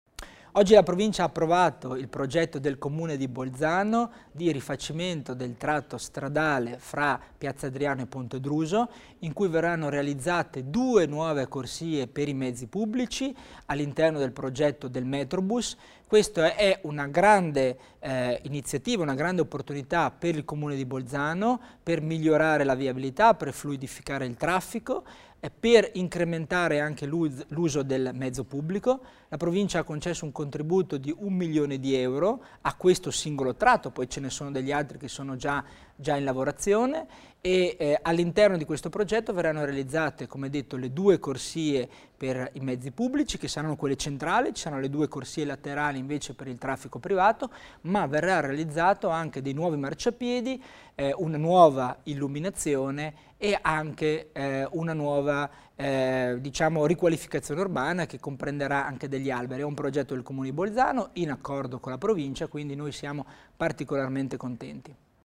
Il Vicepresidente Tommasini spiega il finanziamento al Comune di Bolzano per il nuovo tratto cittadino del Metrobus